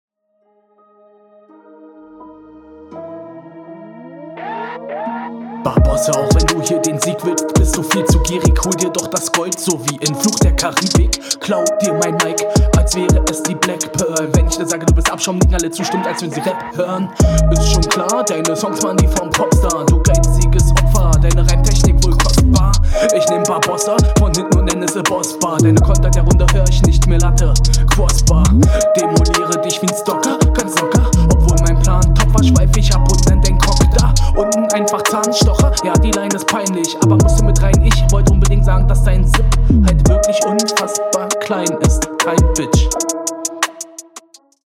Flow wirklich nicht gut, Doubletimepart offbeat.